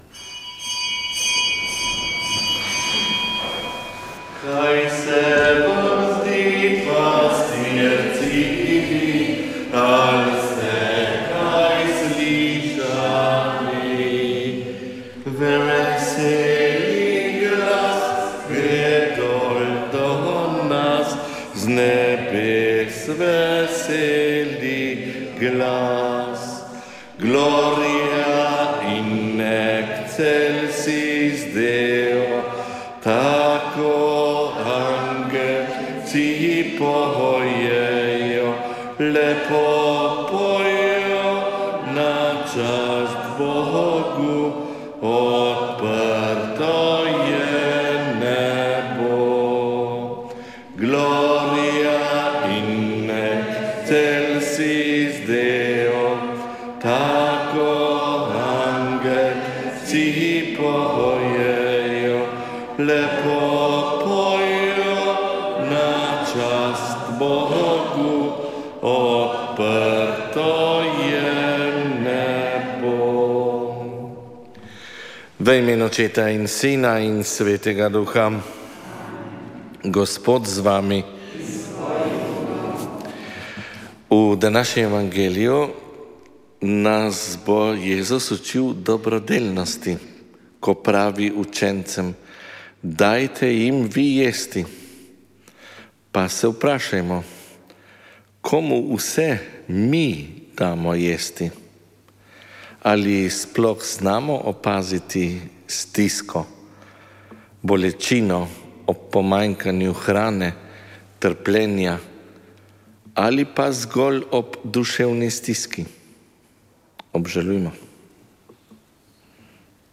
Sv. maša iz cerkve sv. Marka na Markovcu v Kopru 5. 1.
s petjem je sodeloval Zbor sv. Marka.